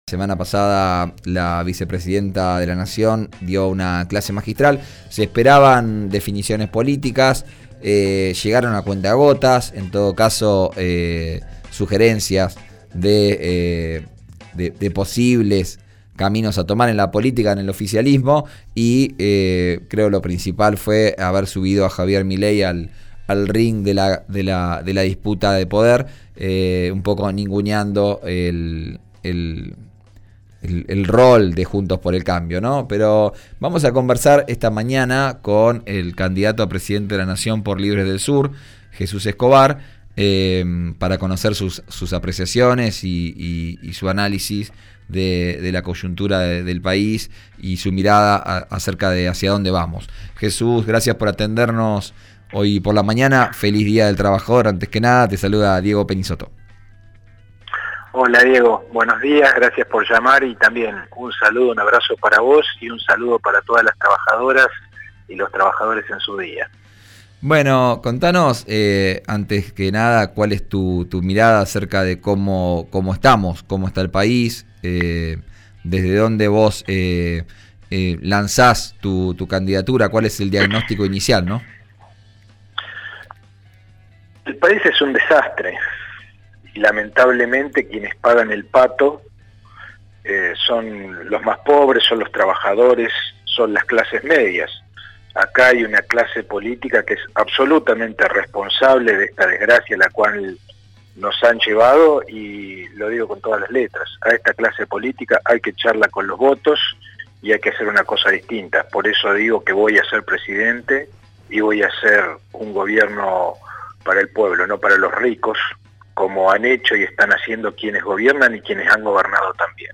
El candidato a presidente de la Nación por Libres del Sur apuntó contra la vicepresidenta. Escuchá la entrevista completa en RÍO NEGRO RADIO.